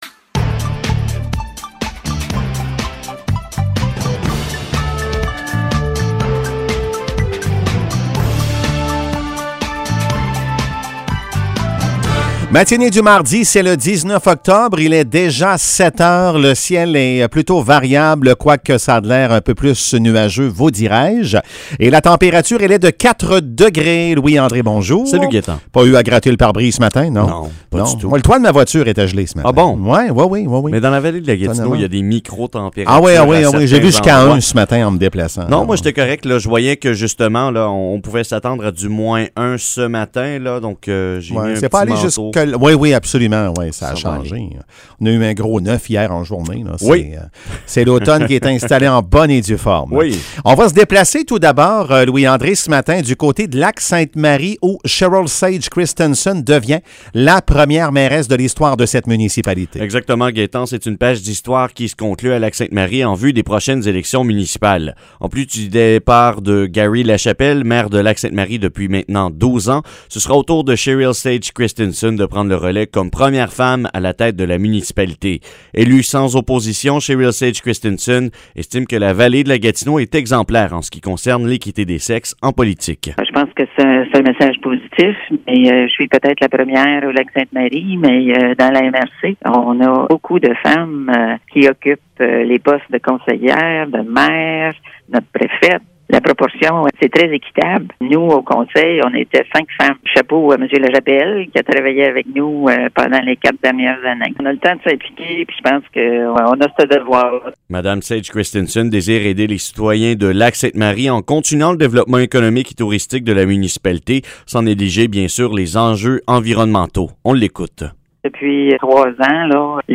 Nouvelles locales - 19 octobre 2021 - 7 h